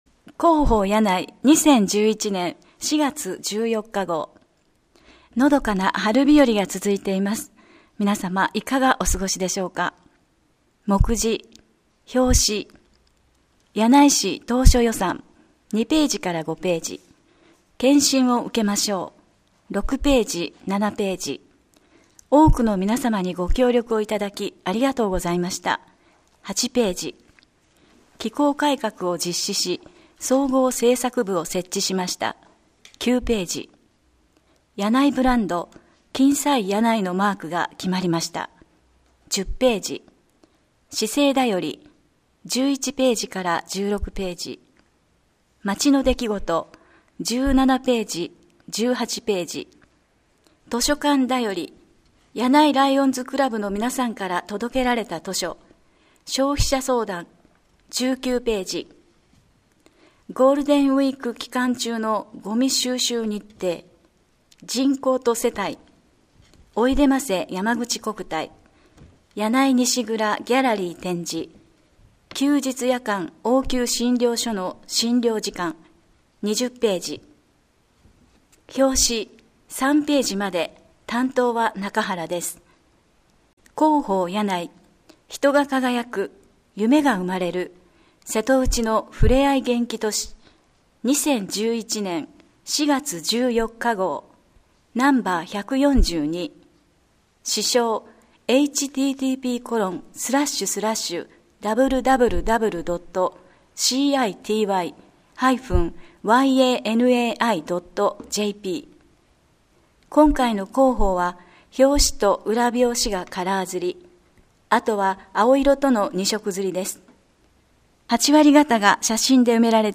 表紙・まちの出来事などのカラー版はこちらから 声の広報（音訳版：発行後1週間程度で利用可能）はこちらから デイジー図書用